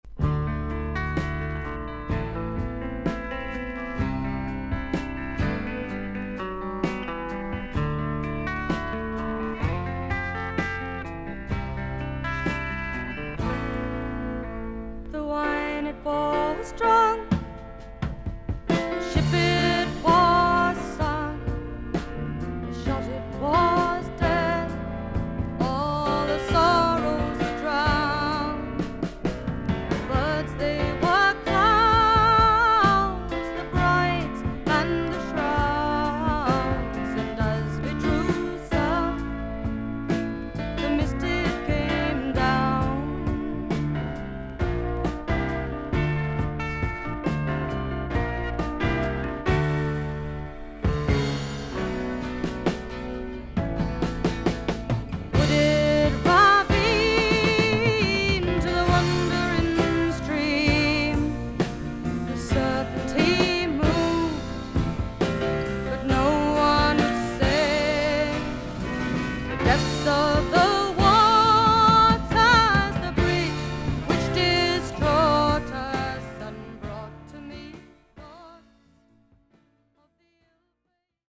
VG++〜VG+ 少々軽いパチノイズの箇所あり。クリアな音です。